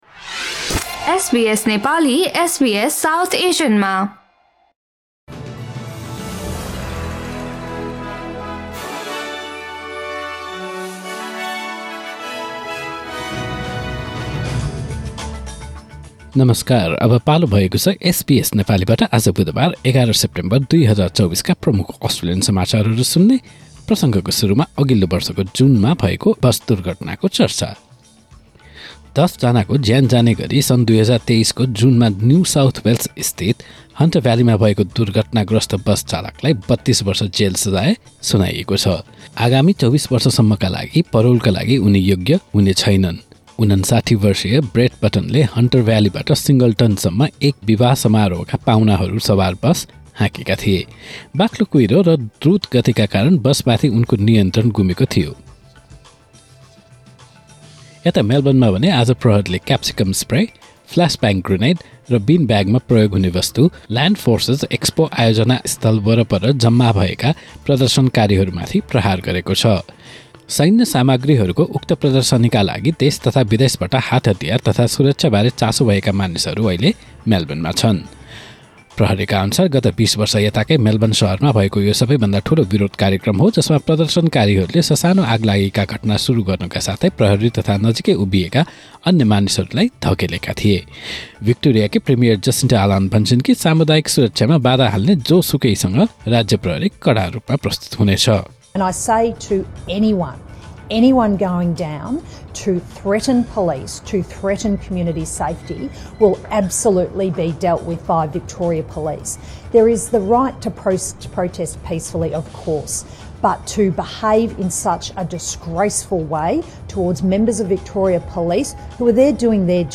SBS Nepali Australian News Headlines: Wednesday, 11 September 2024